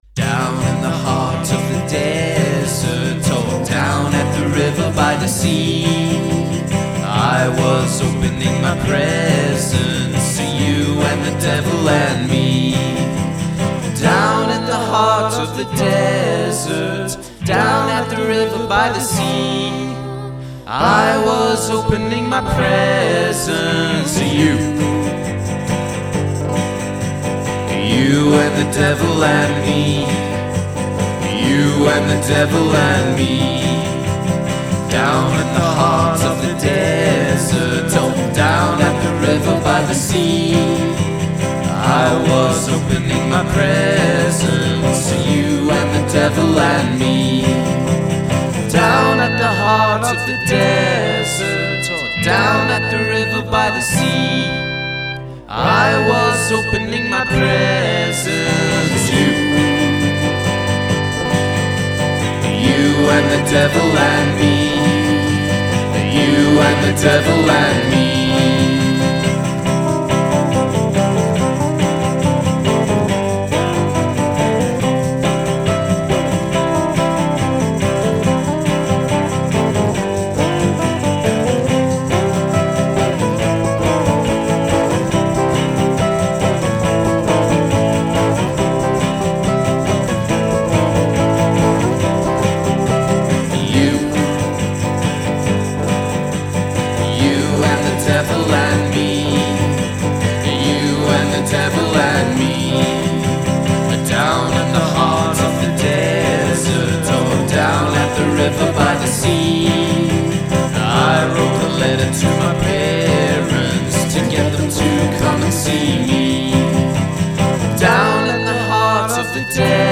Though still thoroughly dark and menacing